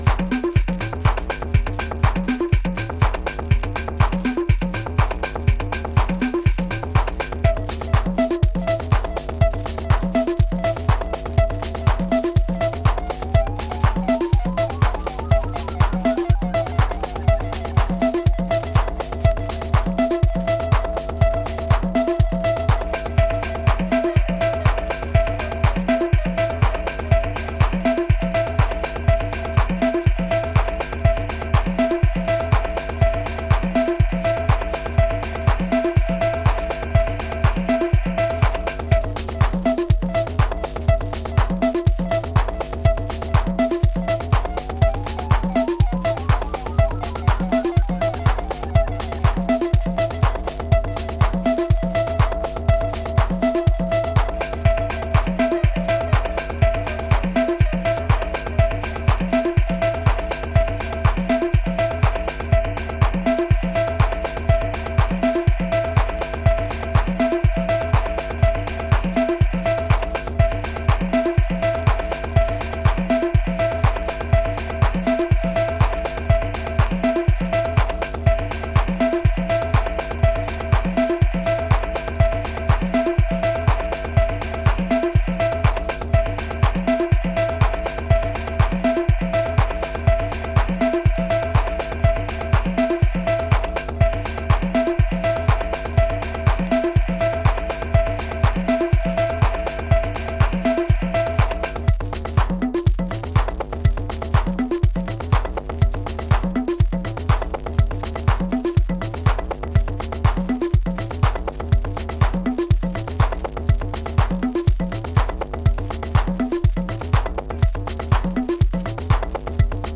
ラフな音像とミニマルな構成がヒプノティックさを醸すアシッド・ハウス